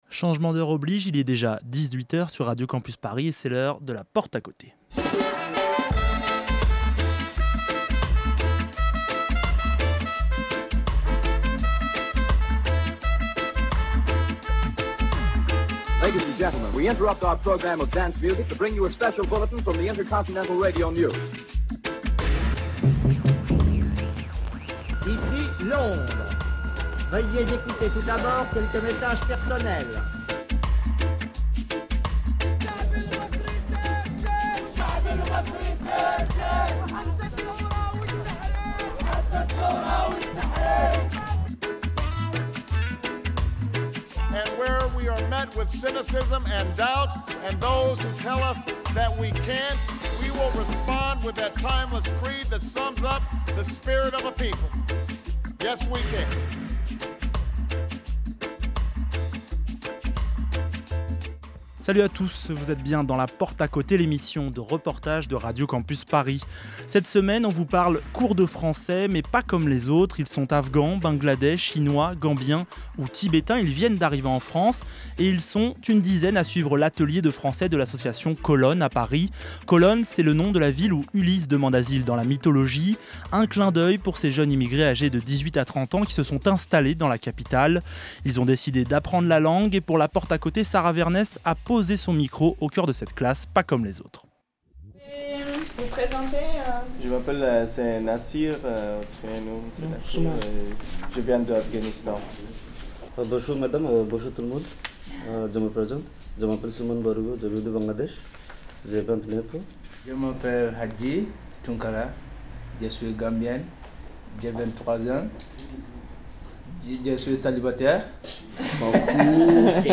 Cette semaine La Porte à Côté vous emmène à Paris dans une classe de langue un peu particulière.